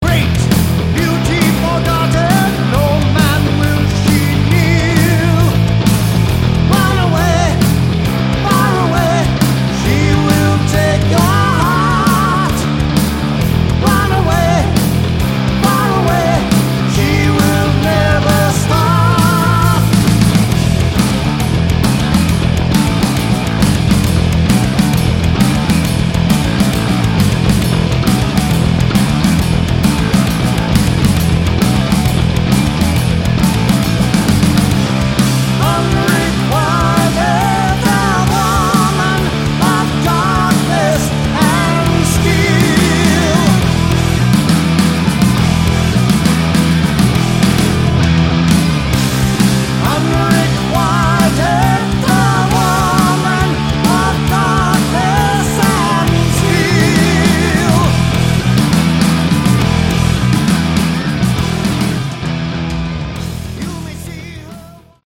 Category: Hard Rock